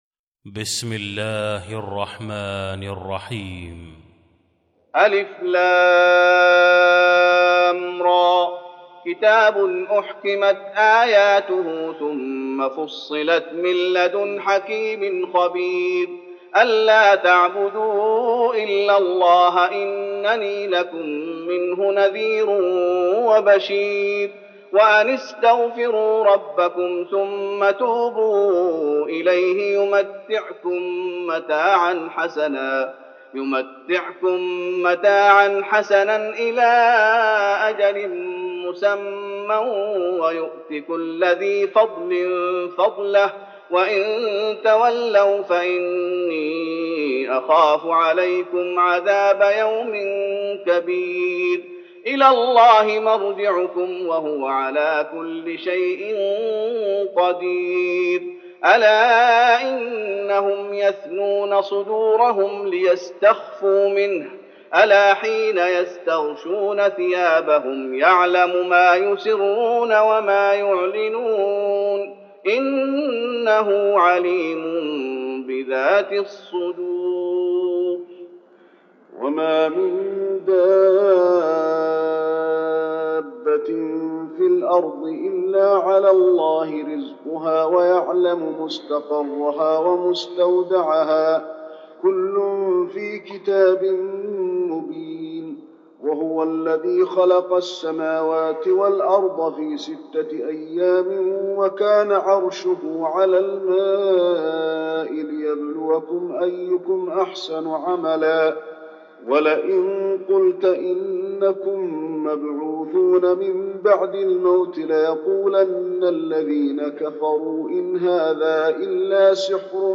المكان: المسجد النبوي هود